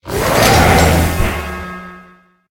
Cri de Zamazenta dans sa forme Bouclier Suprême dans Pokémon HOME.
Cri_0889_Bouclier_Suprême_HOME.ogg